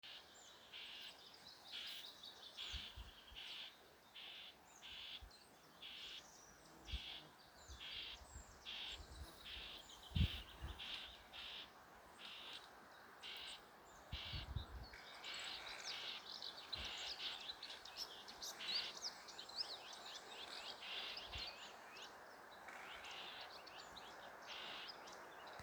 Brūnspārnu ķauķis, Curruca communis
Administratīvā teritorijaLīvānu novads
StatussUztraukuma uzvedība vai saucieni (U)